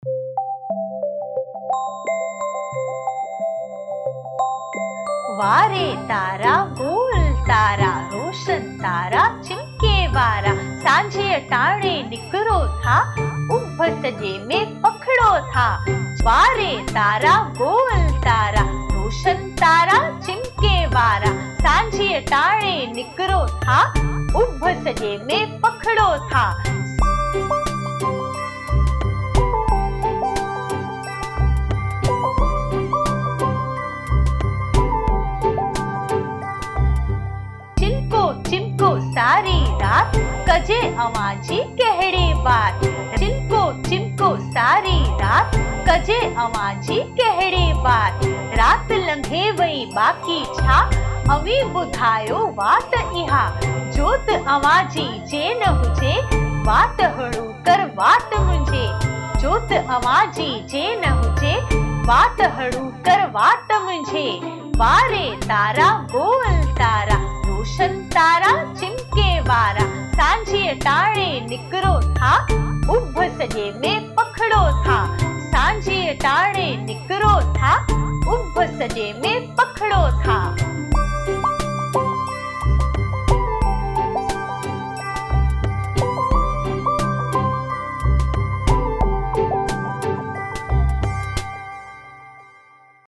Sindhi Nursary Rhymes